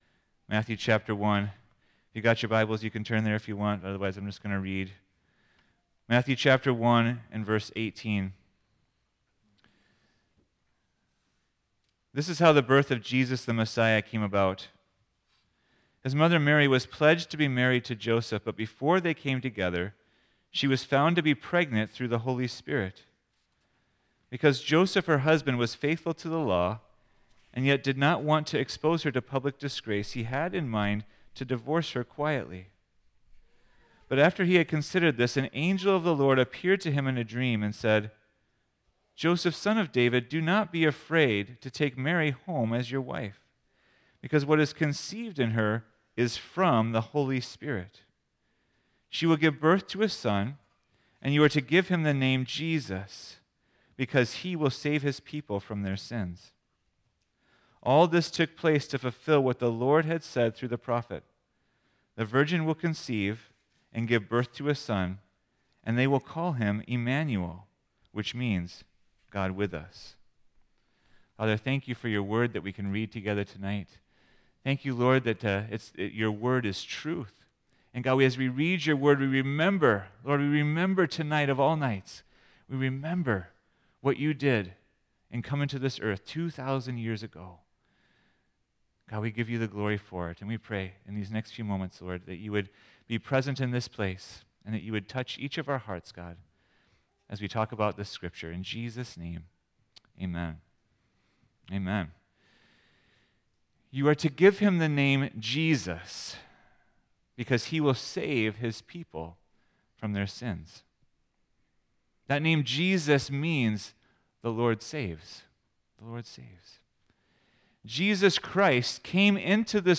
Christmas Eve 2024